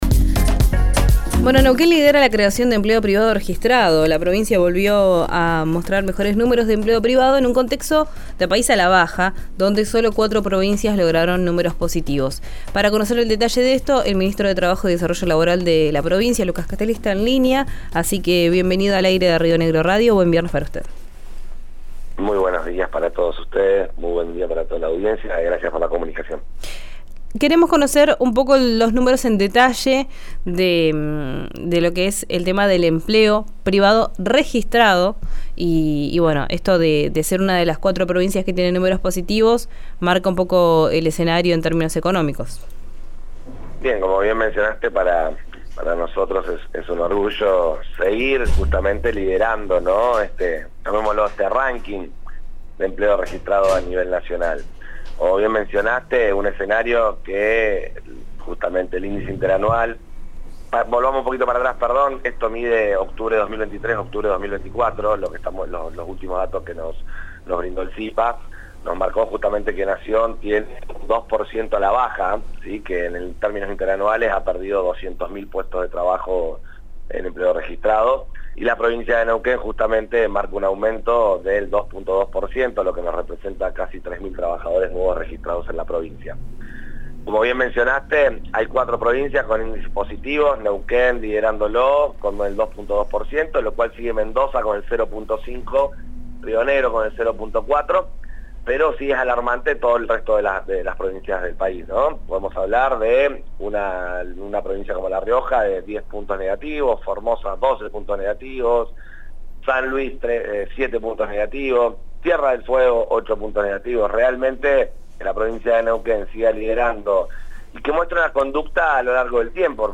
Escuchá al ministro de trabajo de Neuquén, Lucas Castelli, en el aire de RÍO NEGRO RADIO: